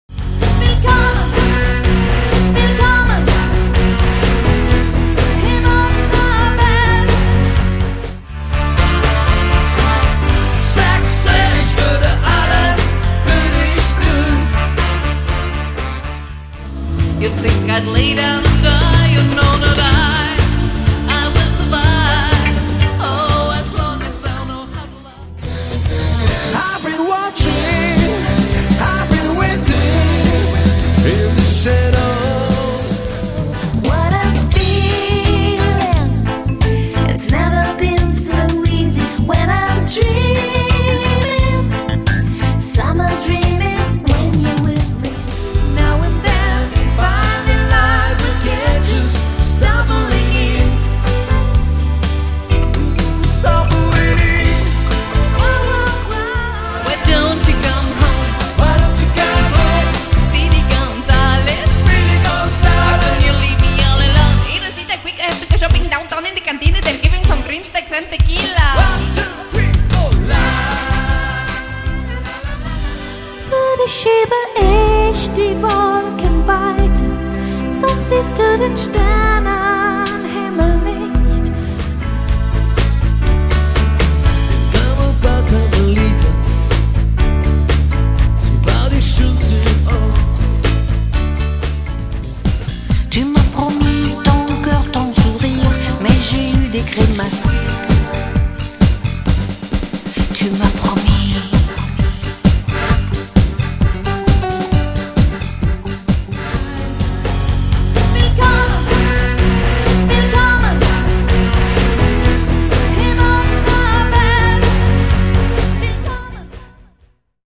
- das DUO klingt bereits wie eine ganze 5-Mann-Band
- ECHTE Live-Musik & Live-Gesang mit 2 SUPER Solostimmen
• Coverband